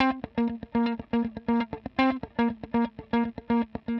120_Guitar_funky_riff_C_7.wav